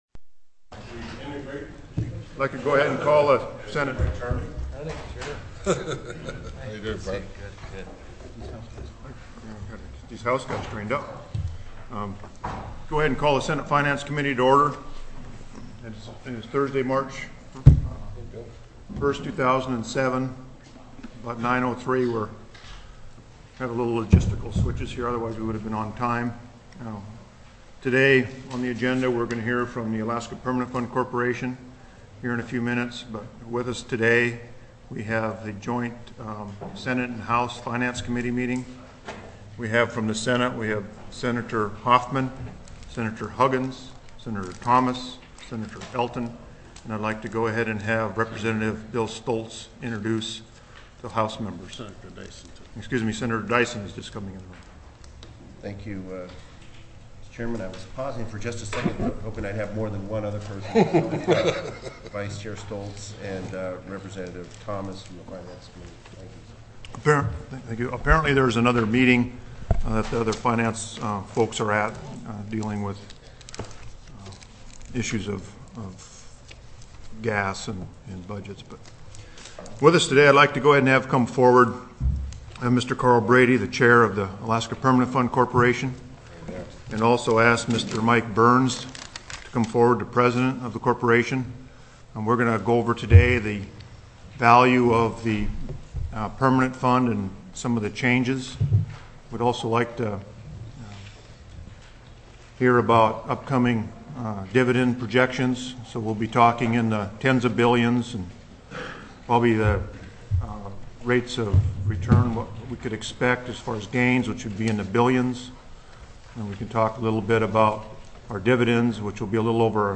Alaska Permanent Fund Corporation Presentation